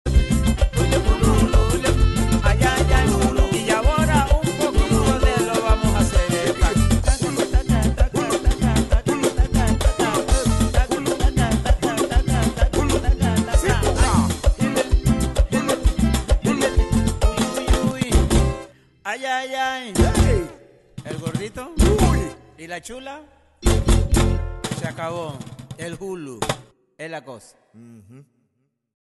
Ritmo punta